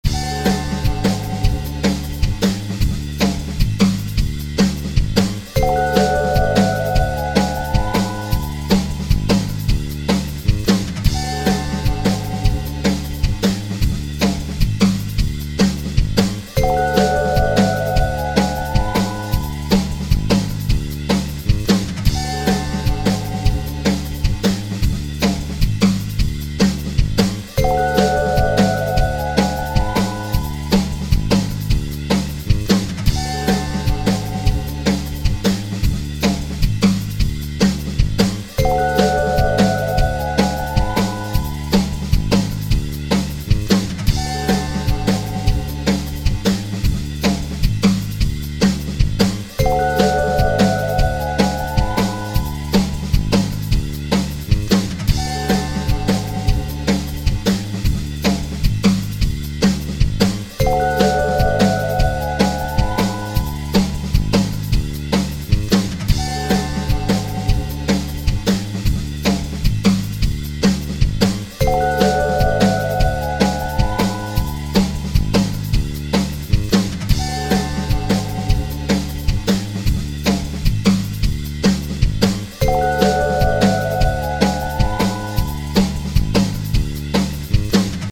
Playbacks pour improviser - Théorie
Lydien b7